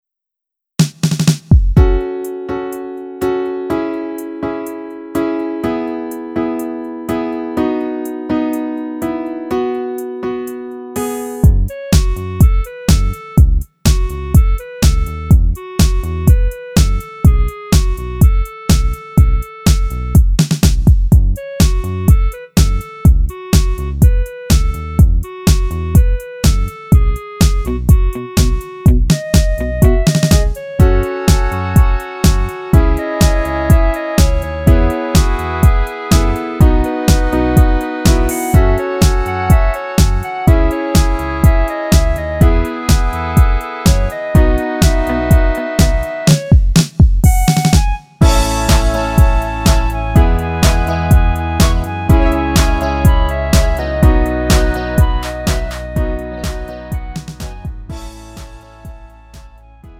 음정 -1키
장르 pop 구분 Lite MR